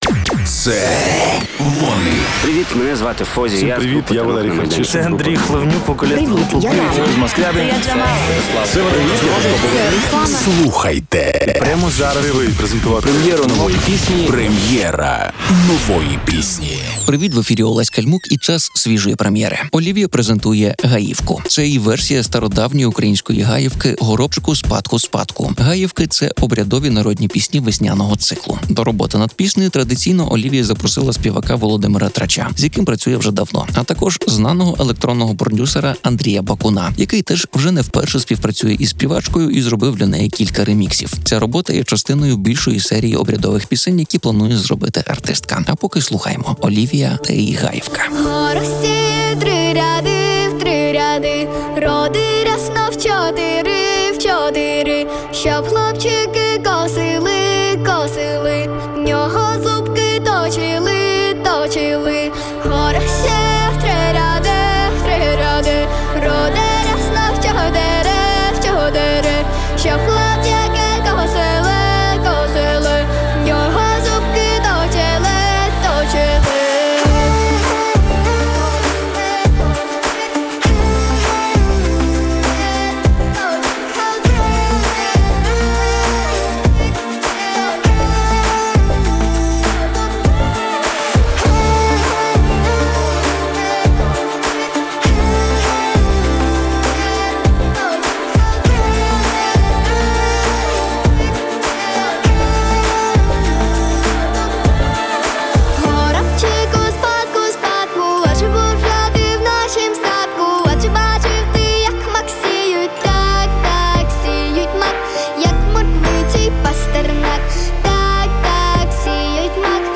стародавньої української гаївки